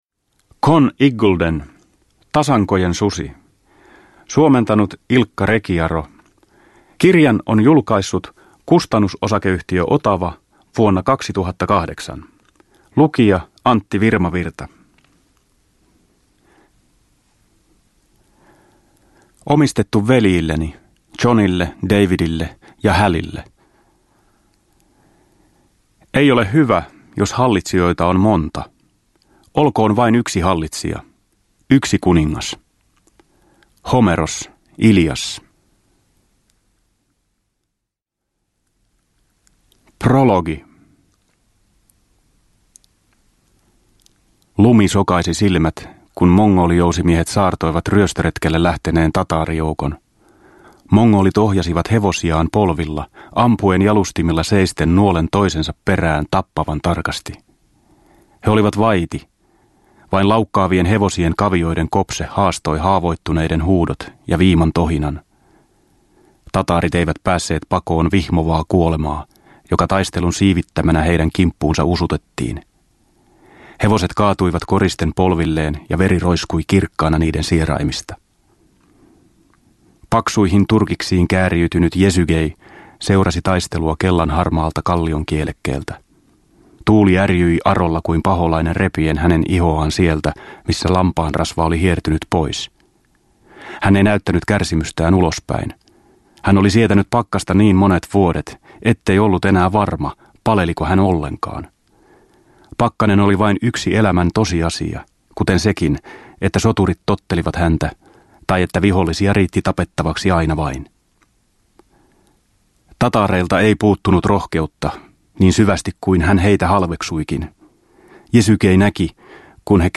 Tasankojen susi – Ljudbok – Laddas ner